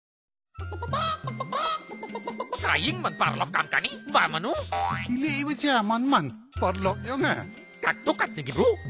This is fourth in the series of five Radio PSA and address backyard farmers and their families. It also uses a performer and a rooster puppet as a creative medium to alert families to poultry diseases and instill safe poultry behaviours.
Radio PSA